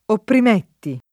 opprim$tti] e opprimei [opprim%i]); part. pass. oppresso [oppr$SSo] — cfr. premere